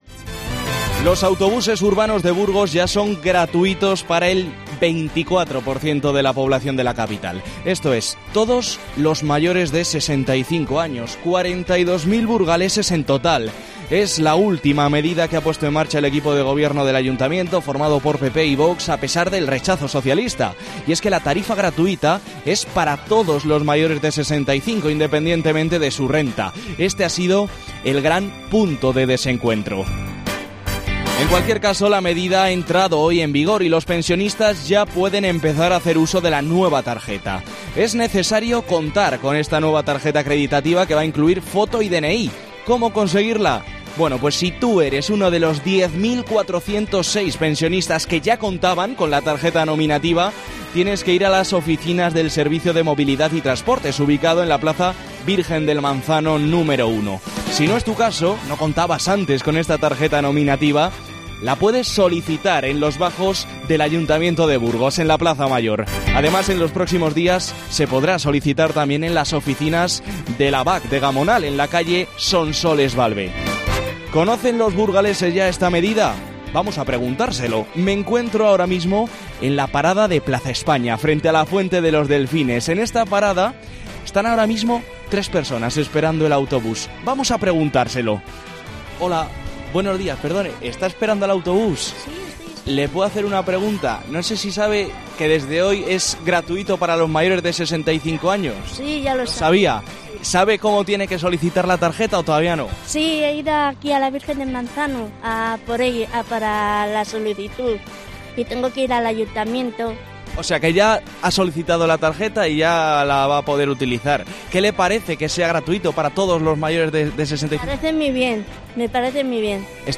Este martes en 'Herrera en COPE' hemos salido a las calles de Burgos para palpar la opinión de los ciudadanos sobre esta nueva medida.